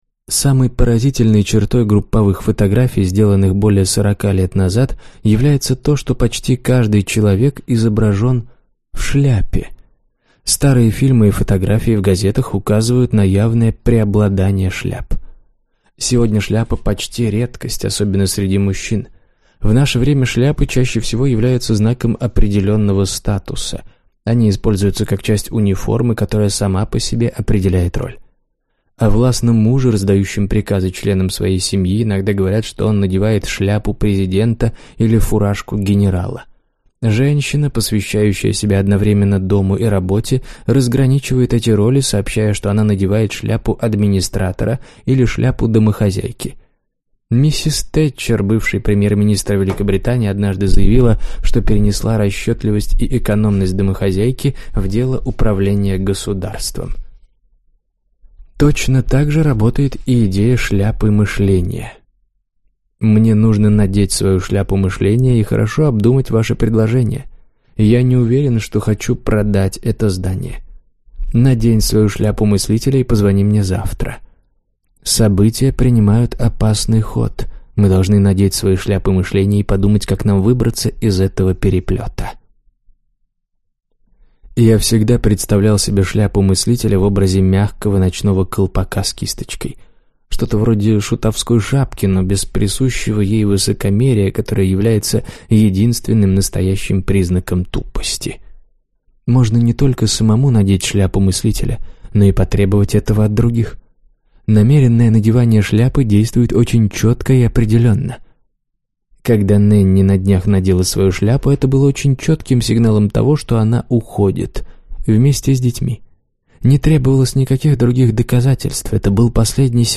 Аудиокнига Управление мышлением | Библиотека аудиокниг